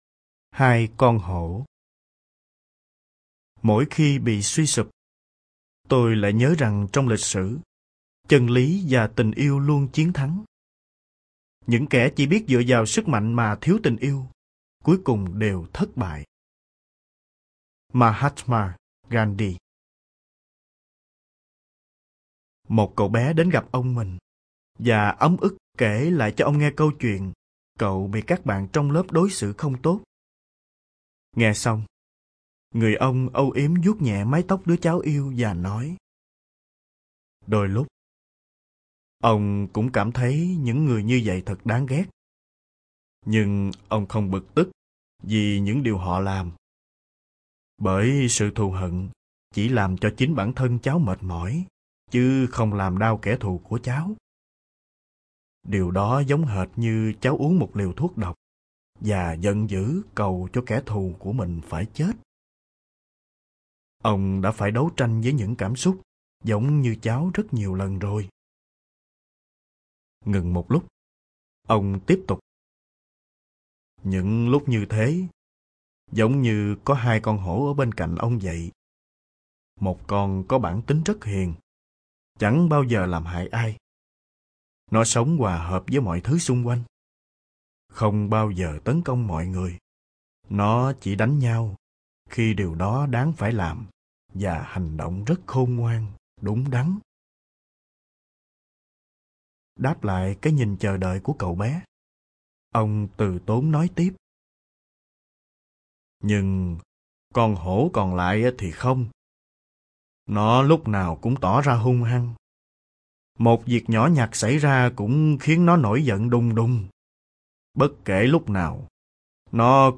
Người đọc